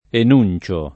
enunciare